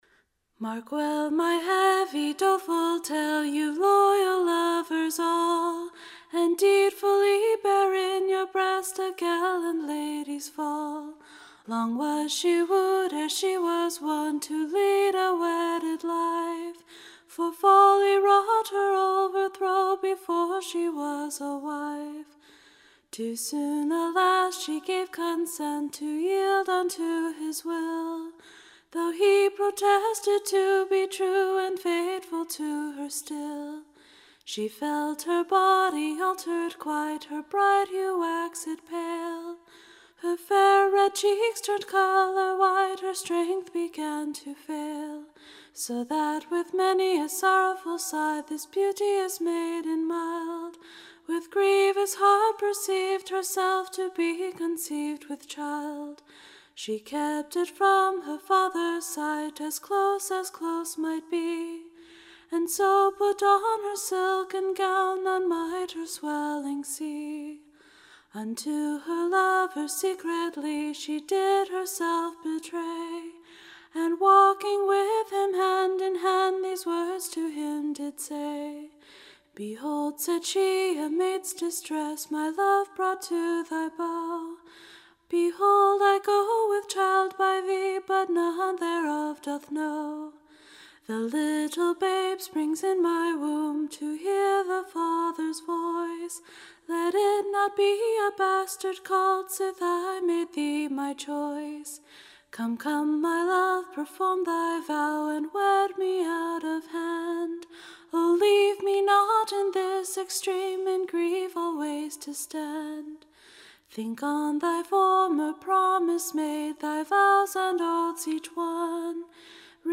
Recording Information Ballad Title A lamentable Ballad of the Ladies Fall, / Declaring how a Gentlewoman through her too much trust came to her en[d] / and how her Lover slew himselfe.